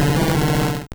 Cri de Tarpaud dans Pokémon Or et Argent.